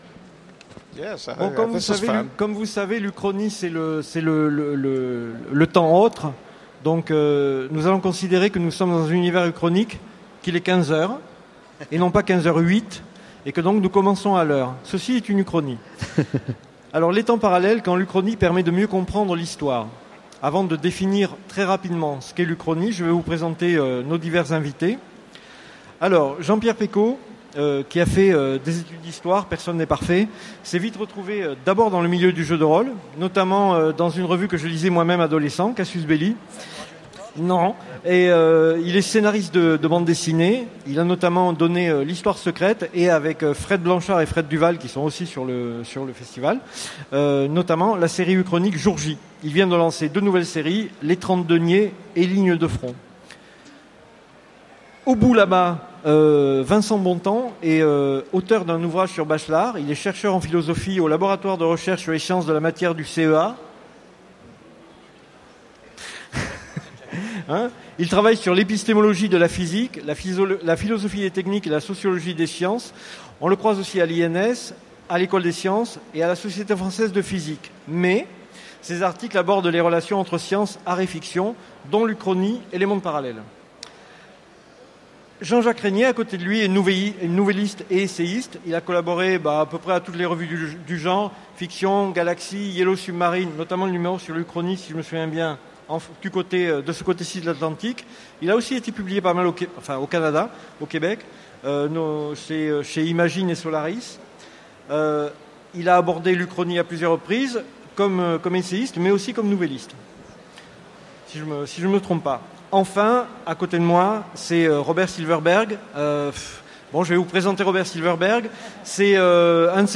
Utopiales 2015 : Conférence Les temps parallèles